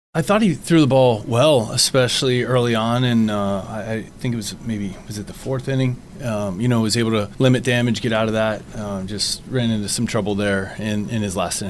Kelly says Mitch Keller didn’t get the result he deserved.